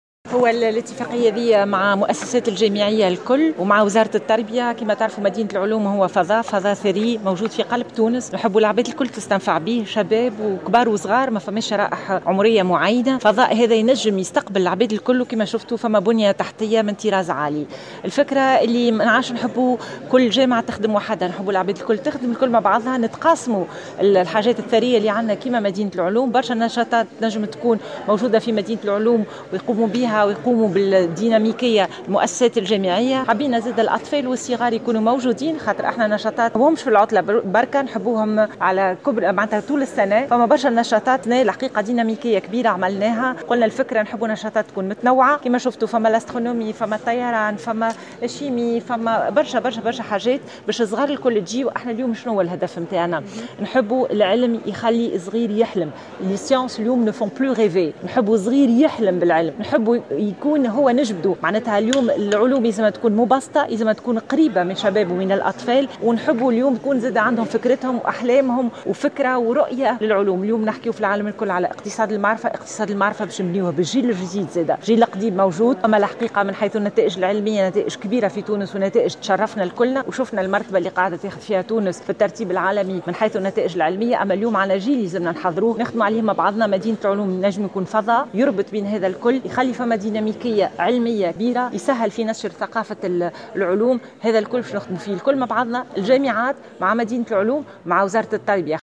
قالت وزيرة التعليم العالي ألفة بن عودة اليوم الثلاثاء إنه تم توقيع اتفاقية بين مدينة العلوم وكل المؤسسات الجامعية ووزارة التربية.